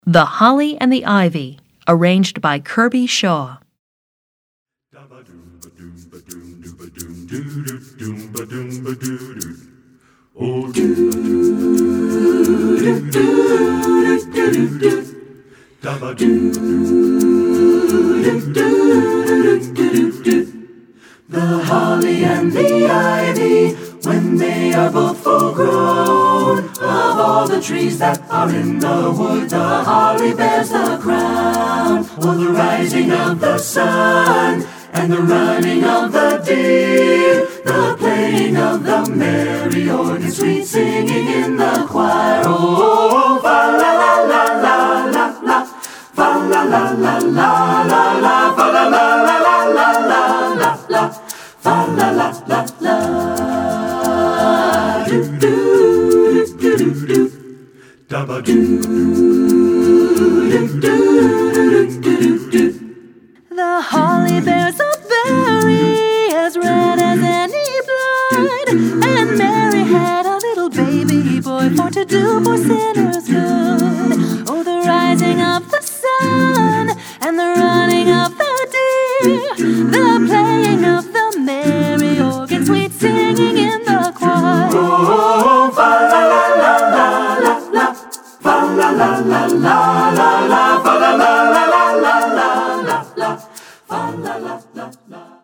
Composer: 18th Century English Carol
Voicing: SATB